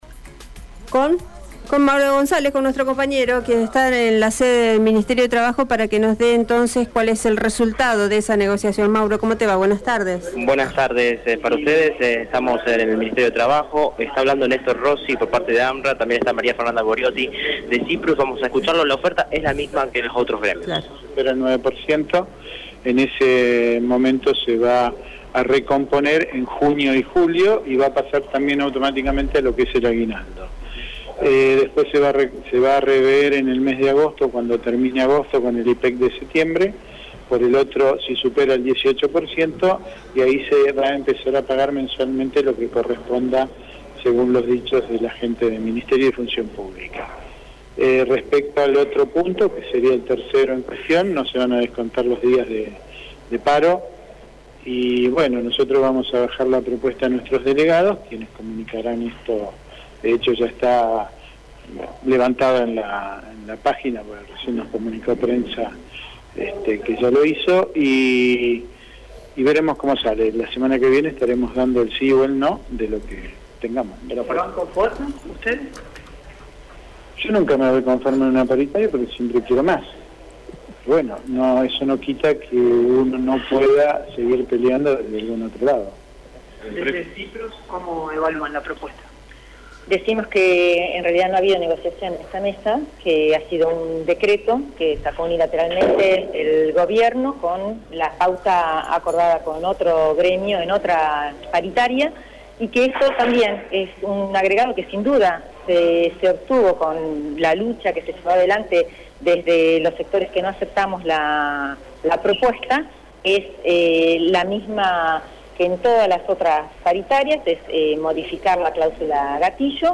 Los secretarios de ambos gremios se expresaron con la prensa luego de la reunión.